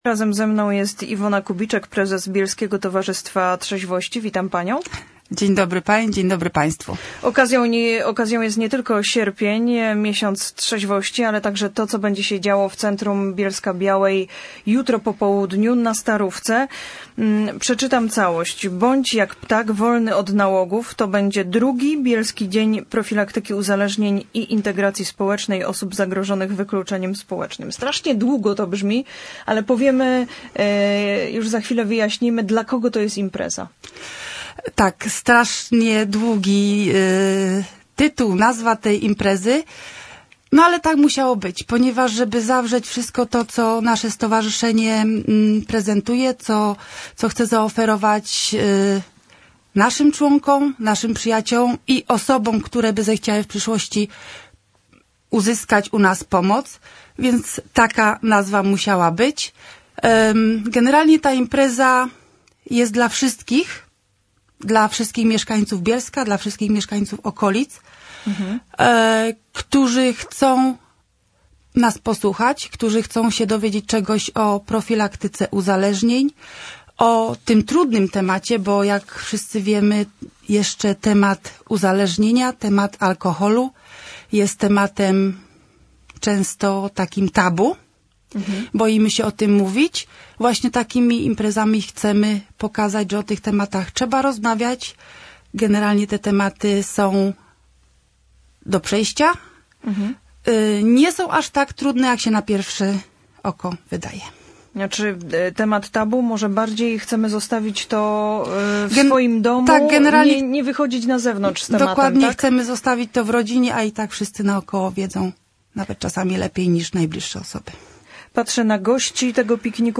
wywiad w radio Bielsko.mp3